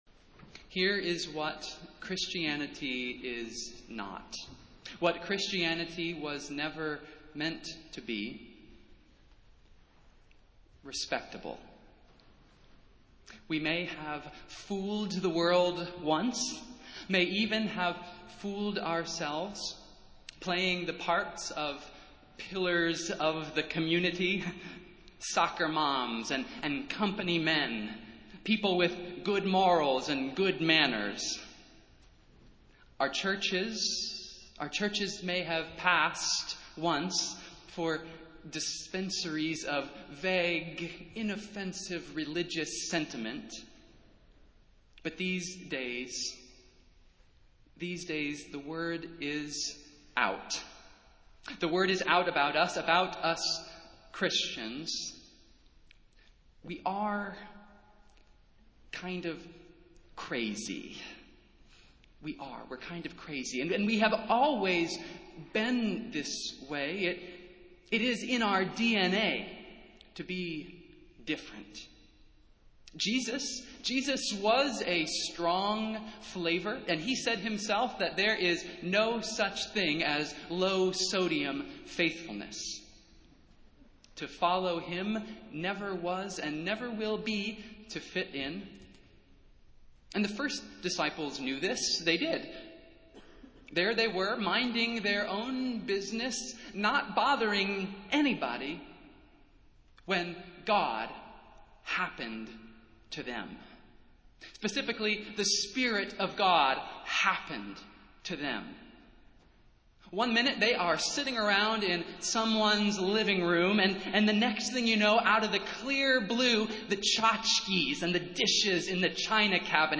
Festival Worship - Pentecost Sunday